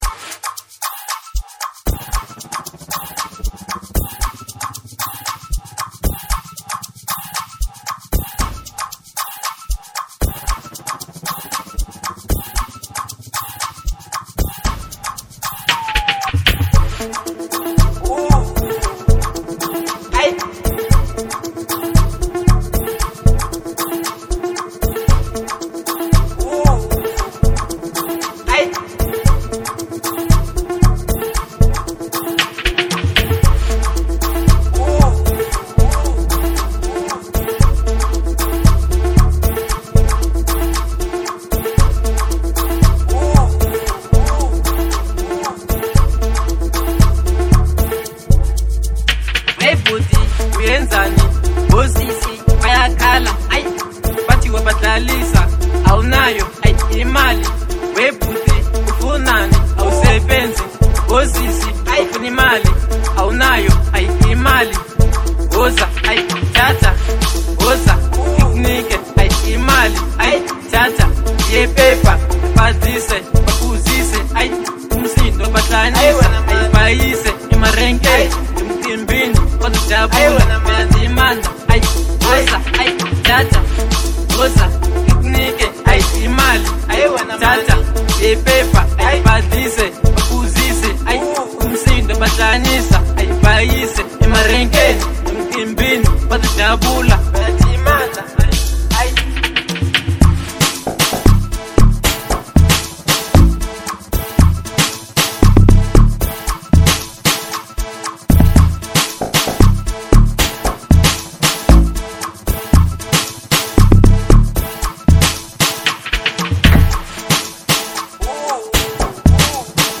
05:56 Genre : Amapiano Size